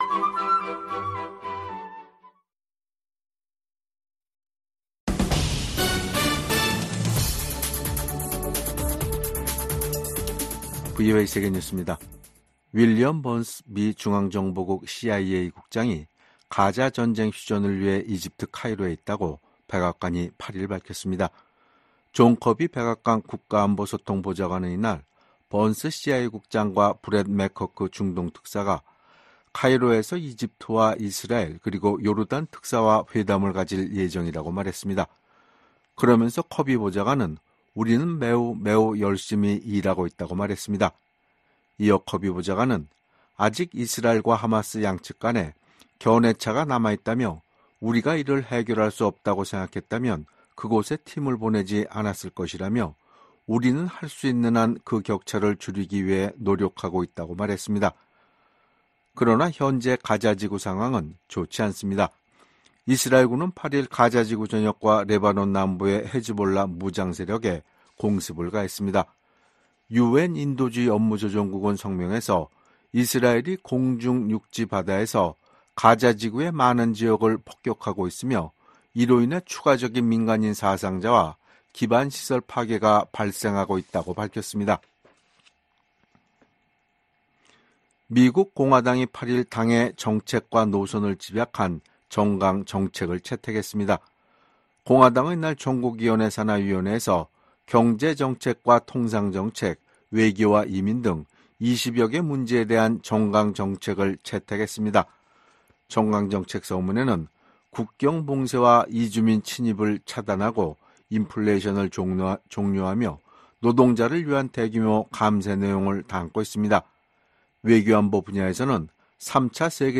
VOA 한국어 간판 뉴스 프로그램 '뉴스 투데이', 2024년 7월 9일 3부 방송입니다. 오늘 9일부터 11일까지 이곳 워싱턴에서는 32개국 지도자들이 참석하는 나토 정상회의가 열립니다.